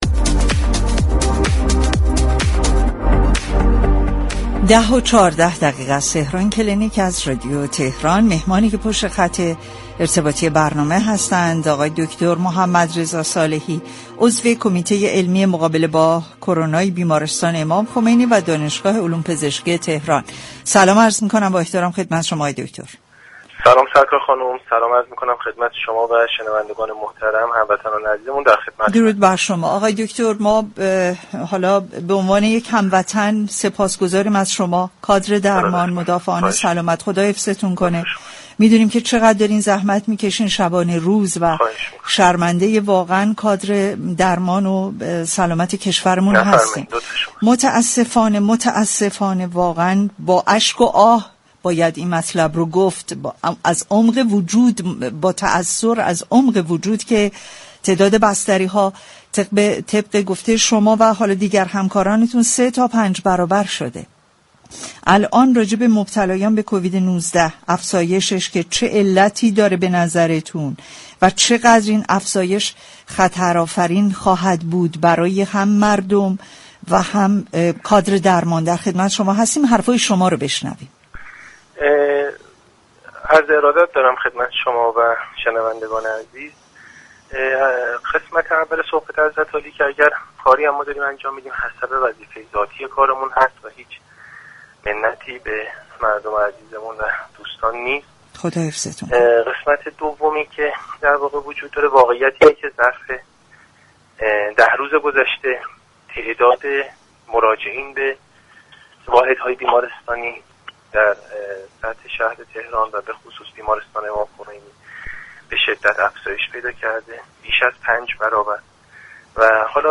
در گفتگو با تهران كلینیك رادیو تهران